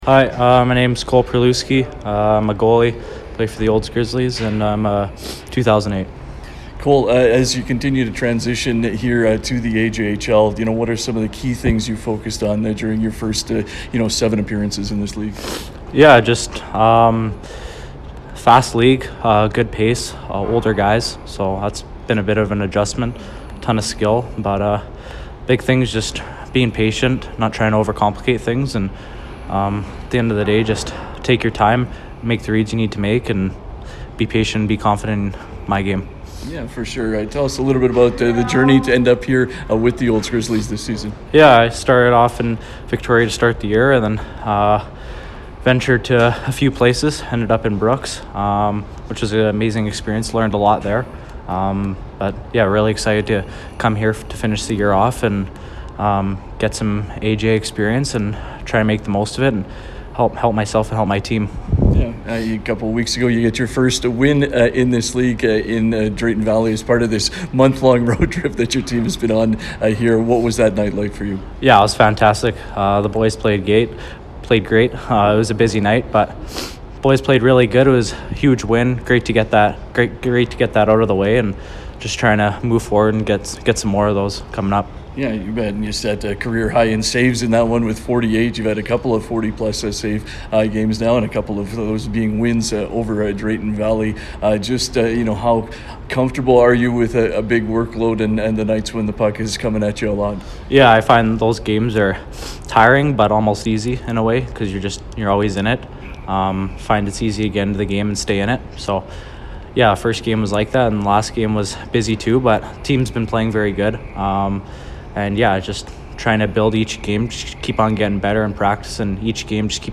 after practice on February 12th